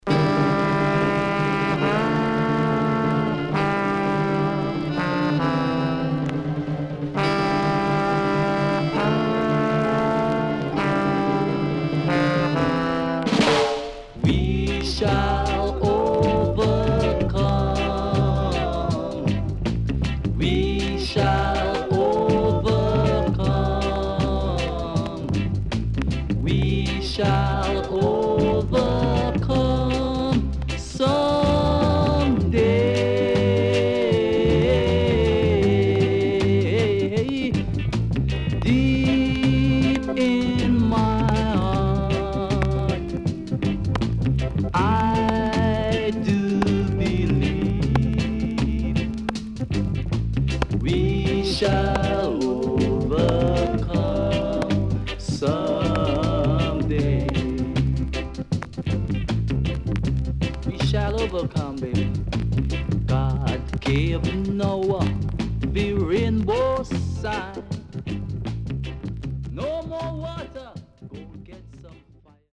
ROCKSTEADY
SIDE A:うすいこまかい傷ありますがノイズあまり目立ちません。